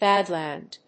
/ˈbæˌdlænd(米国英語)/